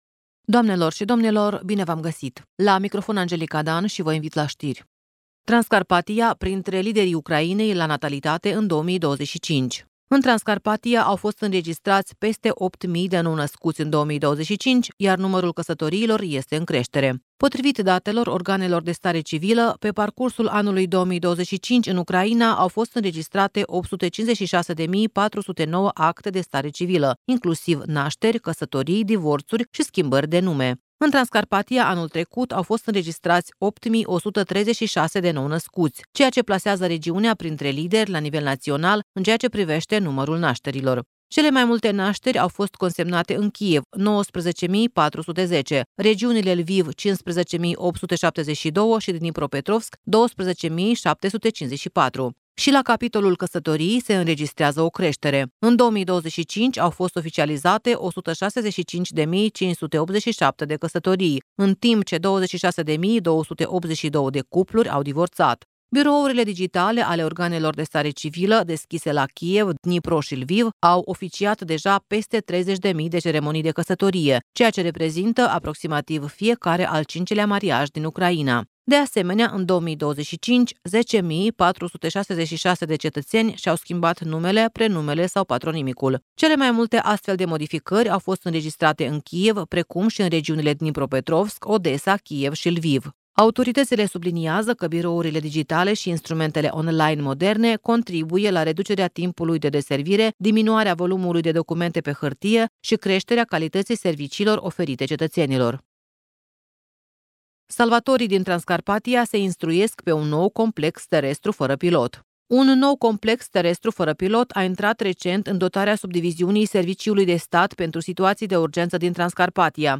Ştiri de la Radio Ujgorod.